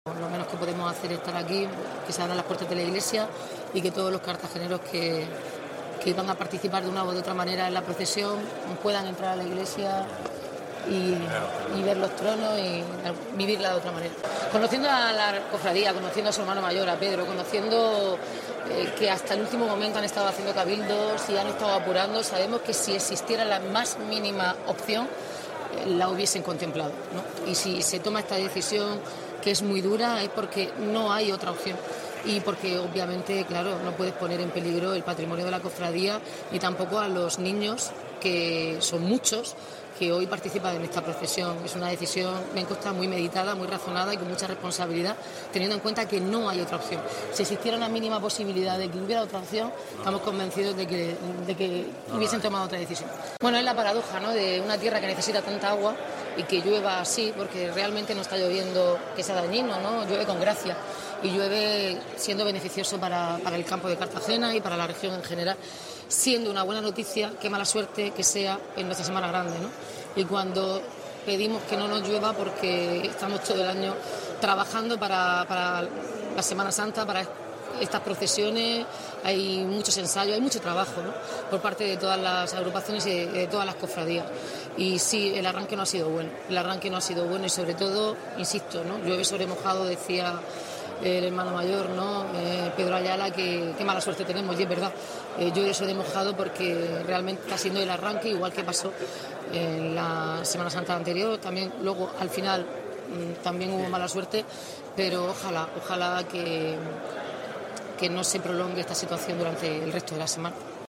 Enlace a Declaraciones de la alcaldesa, Noelia Arroyo, sobre la suspensión de la procesión del Domingo de Ramos